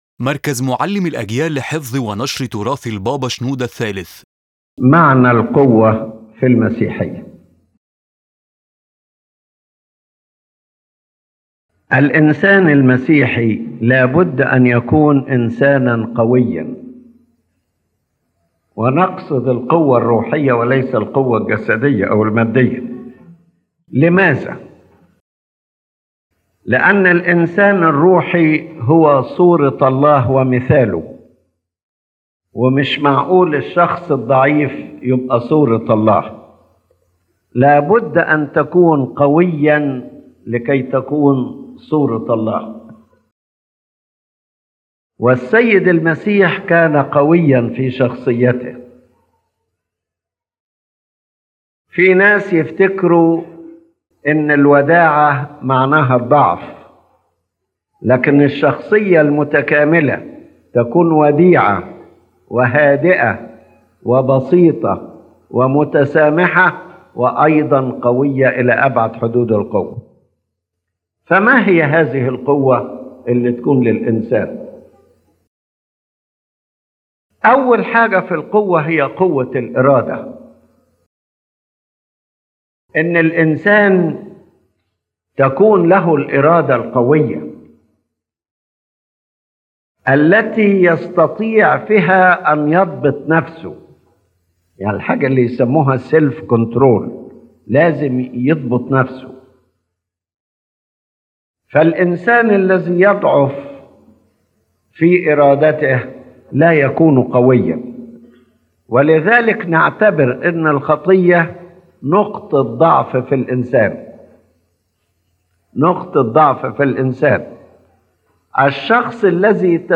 General Idea:His Holiness Pope Shenouda III reflects on the Christian understanding of strength, explaining that true power is not physical or material, but spiritual strength that flows from God’s work within the believer. This inner power enables self-control, endurance in trials, and steadfastness in faith, love, and service.